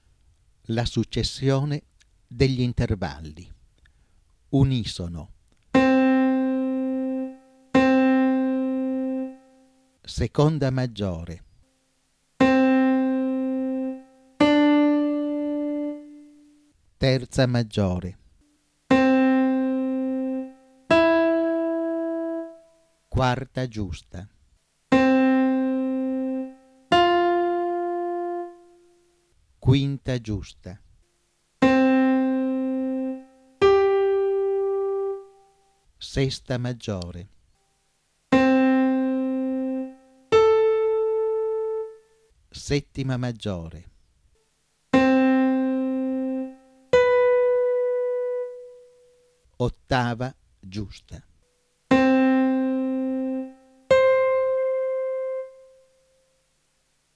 Esempi musicali:
la successione degli intervalli: unisono, 2° Maggiore, 3° Maggiore, 4° Giusta, 5° Giusta, 6° Maggiore, 7° Giusta.
Successione_degli_intervalli.wma